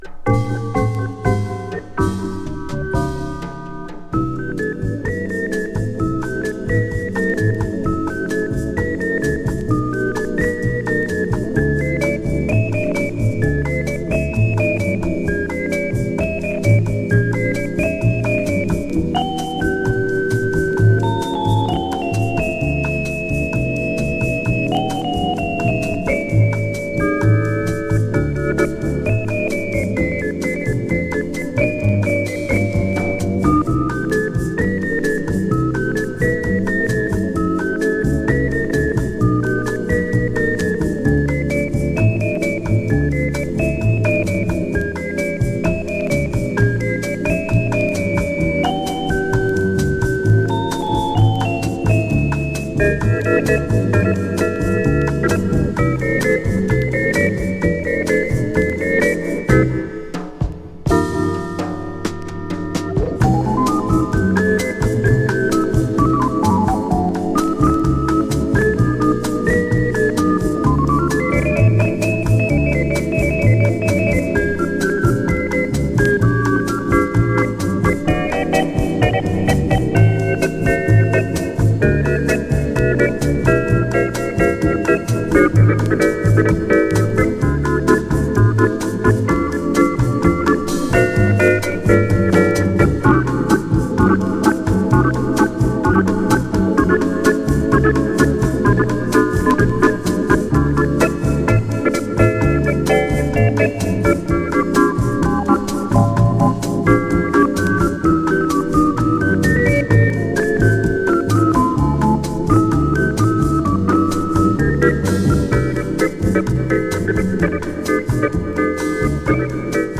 オルガンの響きが心地良すぎるメロウボッサ